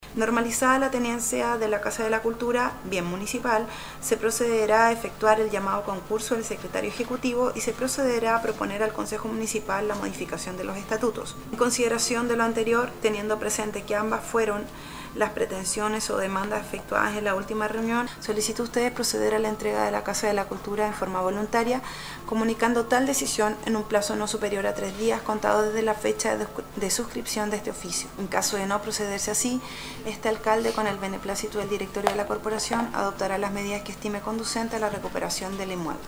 En la sesión de Concejo Municipal llevada a efecto este lunes, se dieron a conocer las cartas que han intercambiado el alcalde de Ancud Carlos Gómez, con los representantes de la toma de este inmueble.